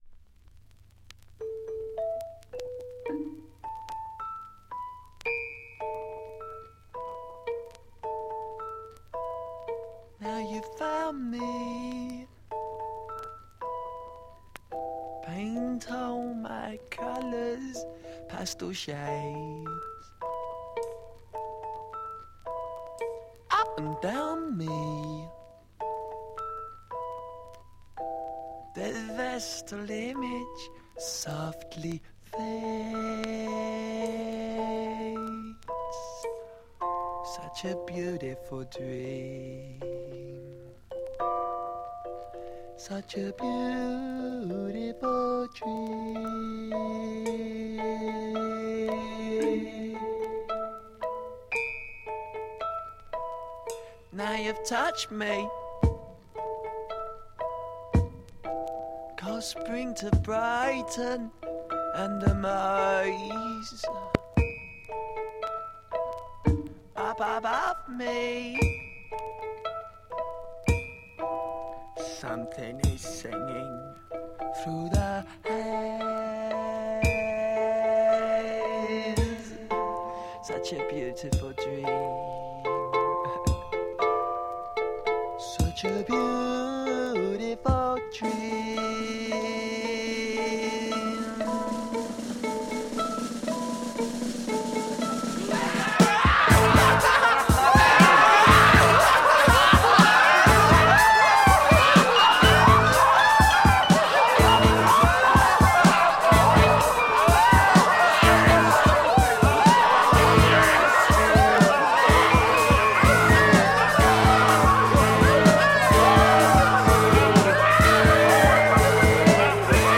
UK Glam Psych
Interesting glam single with a moody psych number.